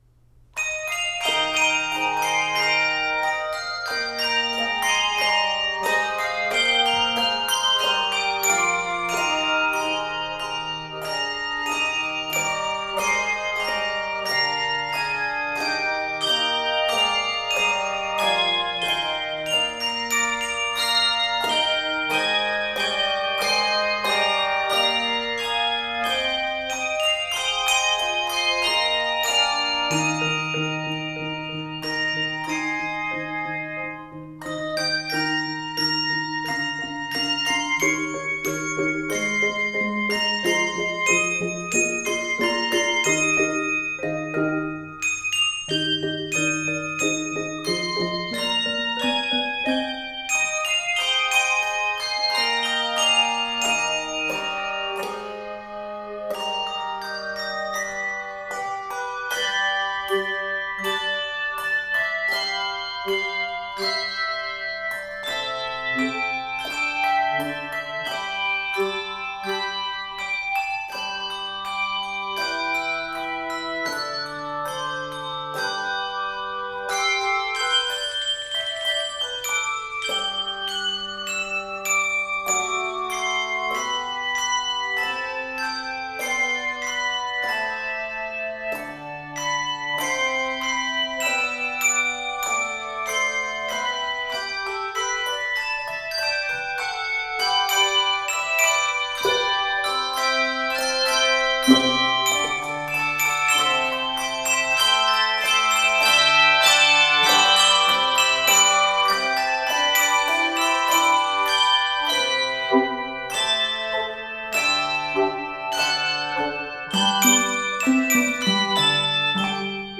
jubilant arrangement combining two different hymn tunes